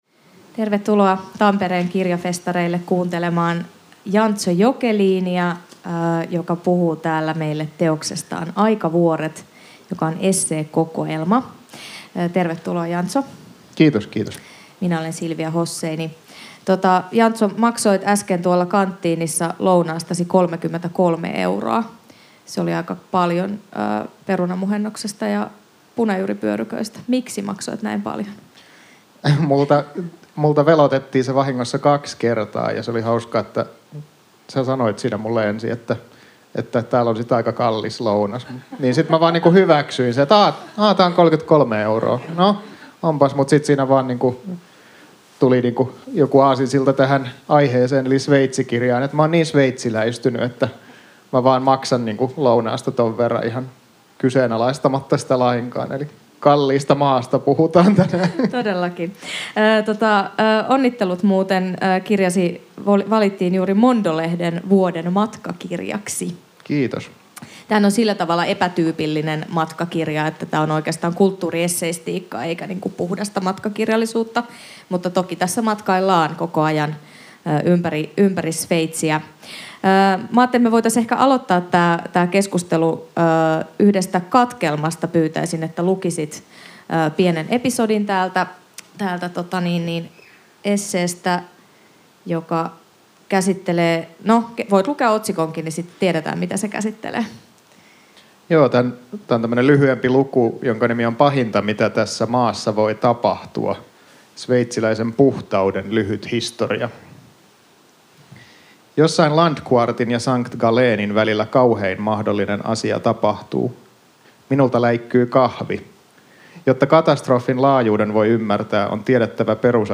Taltiointi Tampereen Kirjafestarit 3.12.2023.